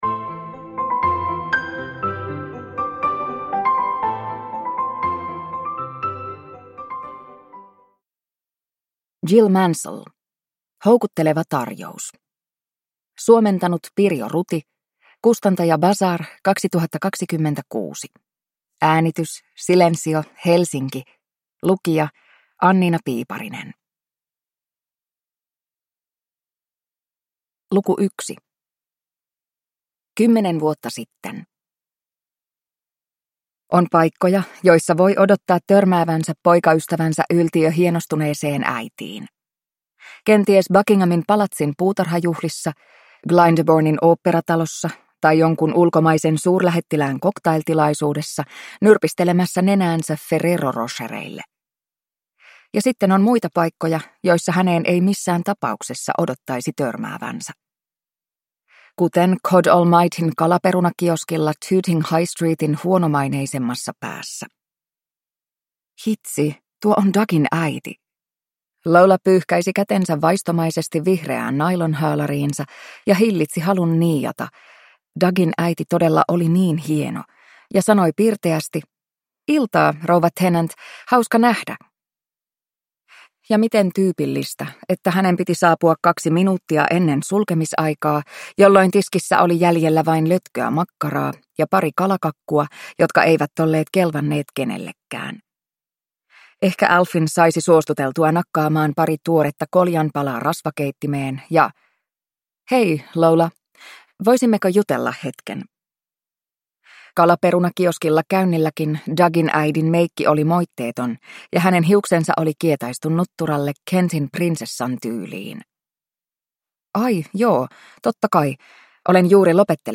Houkutteleva tarjous – Ljudbok